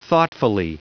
Prononciation du mot thoughtfully en anglais (fichier audio)
Prononciation du mot : thoughtfully